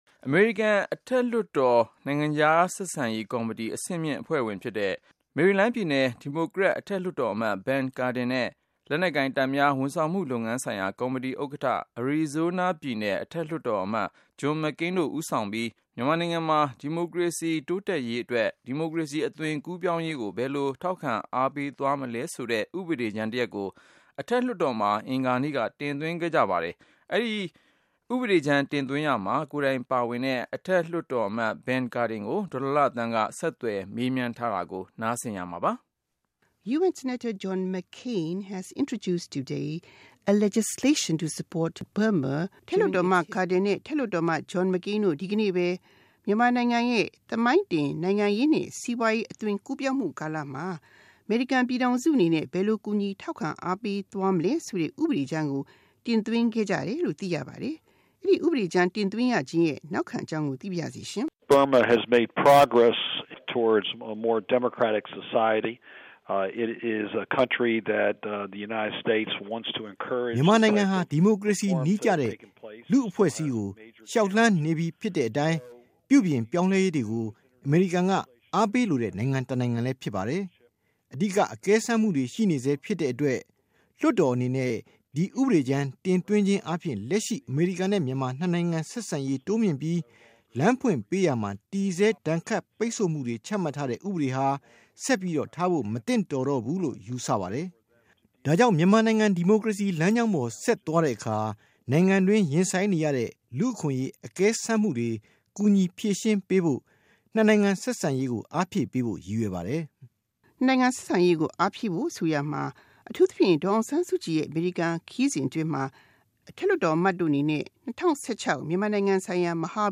၂၀၁၆ မြန်မာမဟာဗျူဟာ ဥပဒေကြမ်း တင်သွင်းသူ အထက်လွှတ်တော်အမတ် Ben Cardin နဲ့ မေးမြန်းခန်း